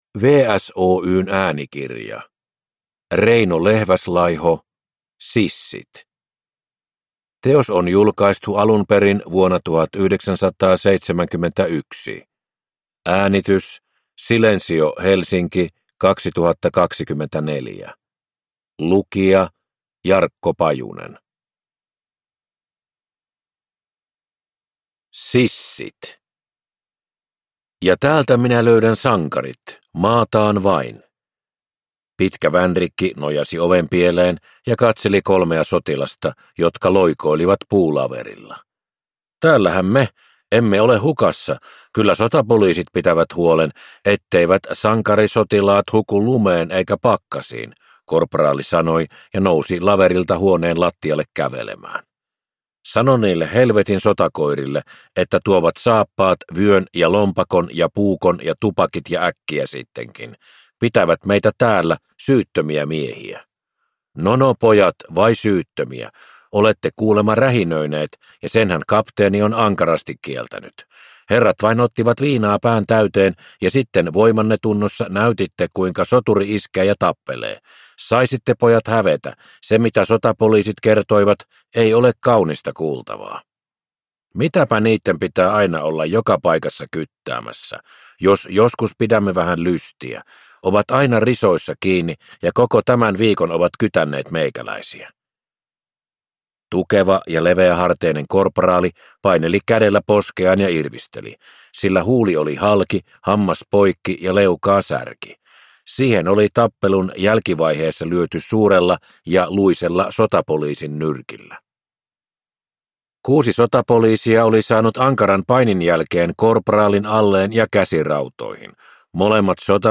Sissit – Ljudbok